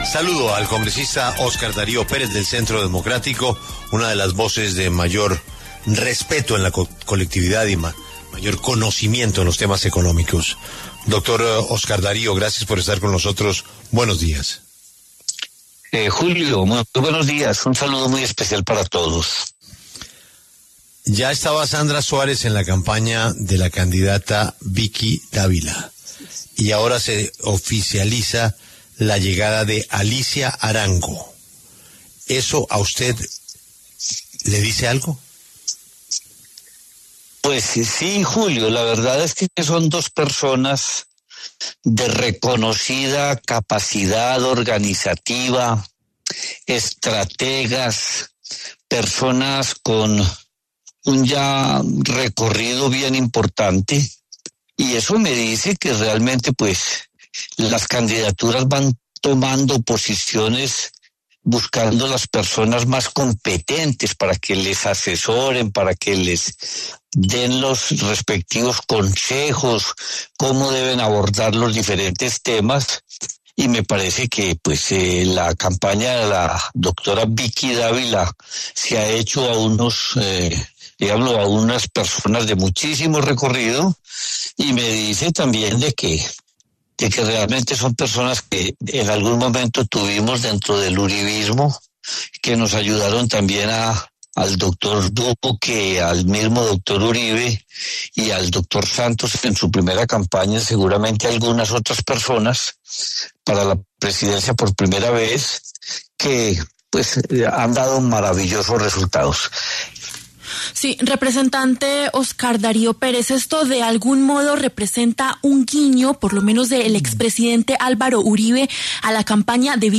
El congresista Óscar Darío Pérez se refirió en La W a la llegada de Alicia Arango a la campaña presidencial de Vicky Dávila.